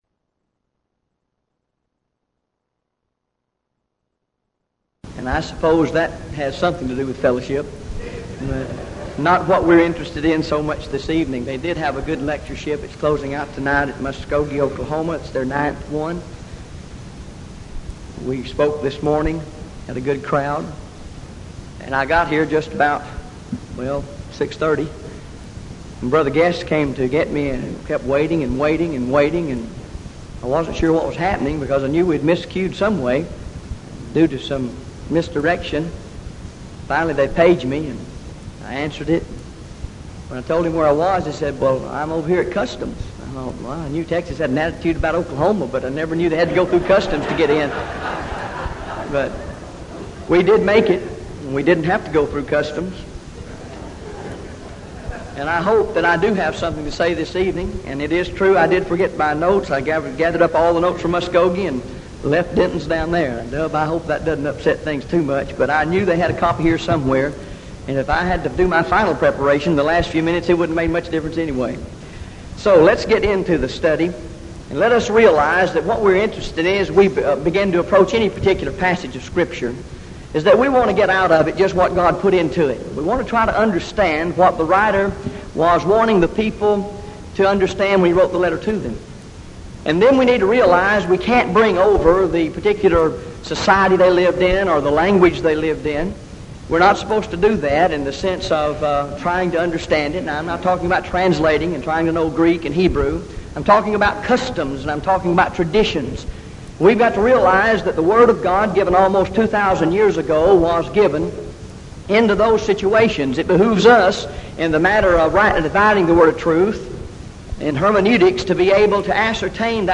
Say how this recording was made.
Event: 1987 Denton Lectures